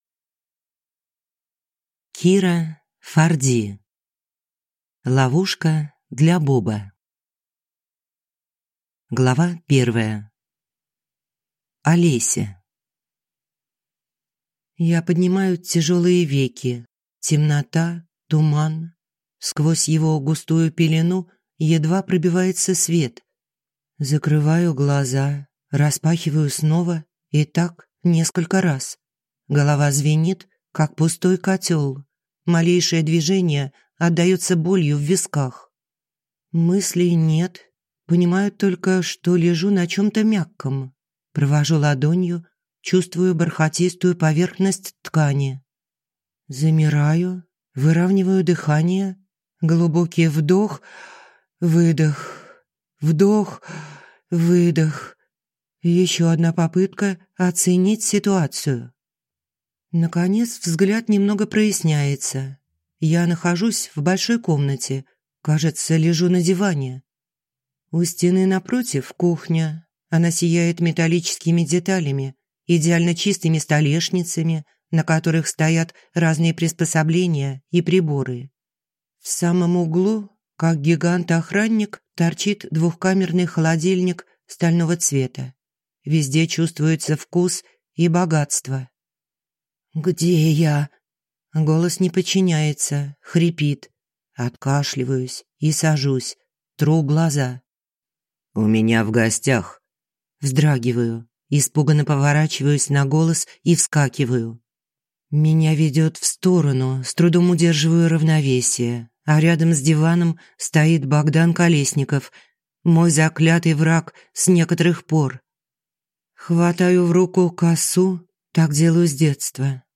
Аудиокнига Ловушка для Боба | Библиотека аудиокниг